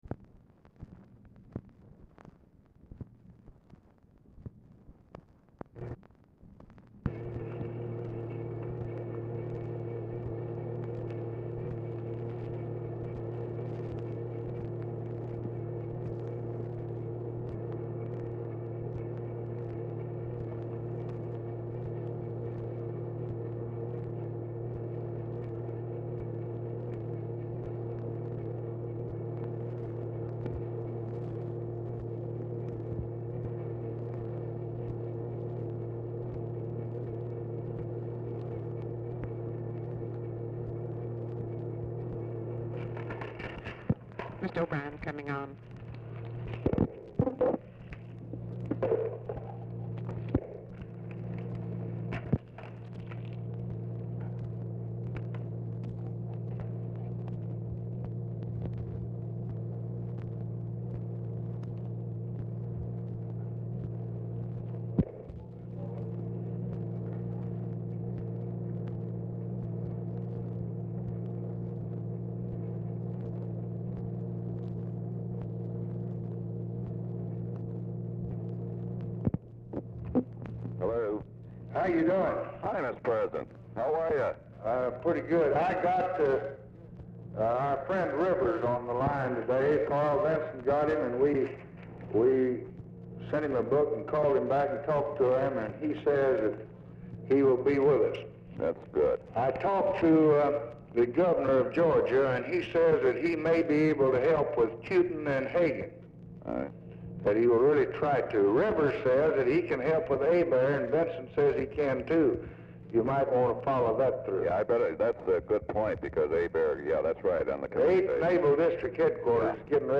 1:20 OFFICE NOISE PRECEDES CALL
Format Dictation belt
Specific Item Type Telephone conversation